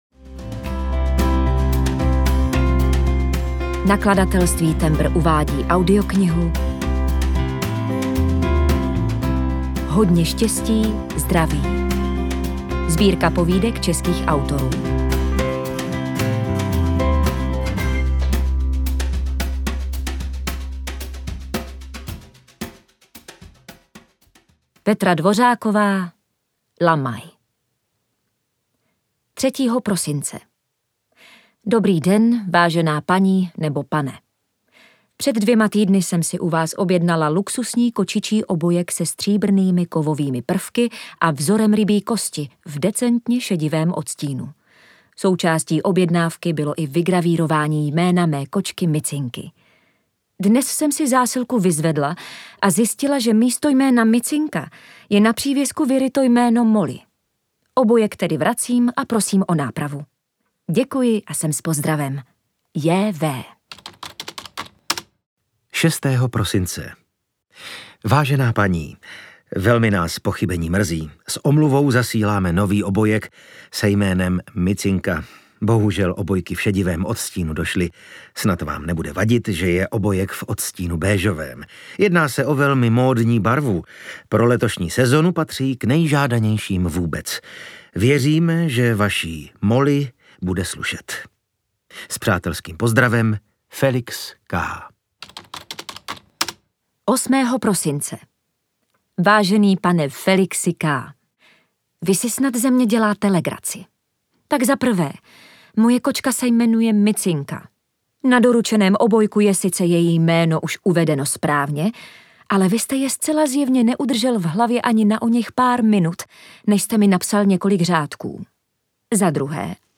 Audiobook
Audiobooks » Short Stories